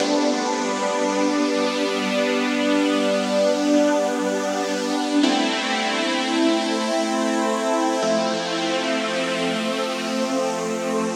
Index of /DESN275/loops/Loop Set - Futurism - Synthwave Loops
BinaryHeaven_86_C_Pad.wav